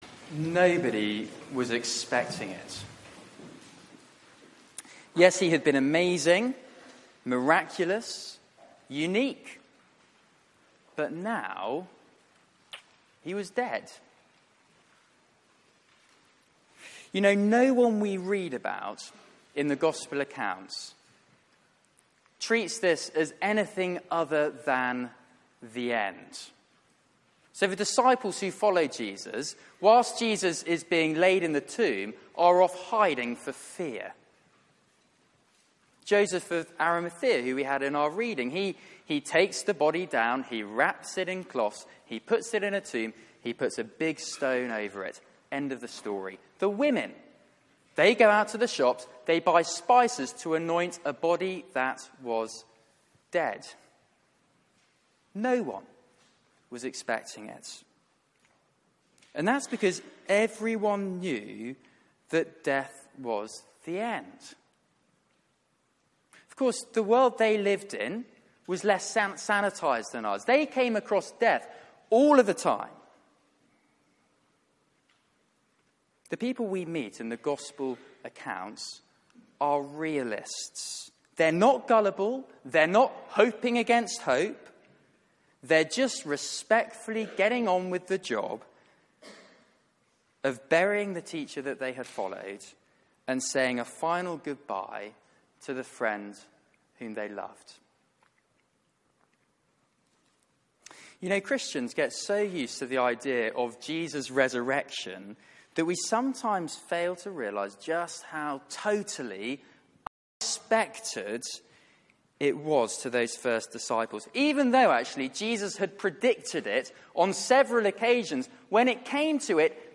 Media for 6:30pm Service on Sun 01st Apr 2018 18:30 Speaker
Series: King's Cross Theme: The risen King Sermon